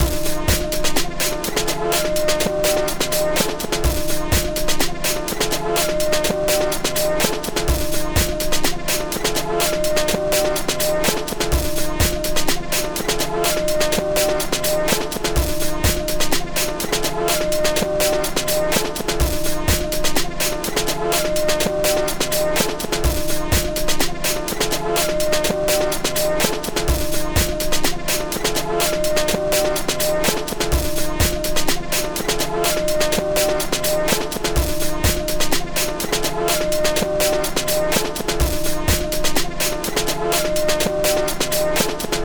Tänker att den kan vara lite krautrockig och repetitiv, tänk typ suicide.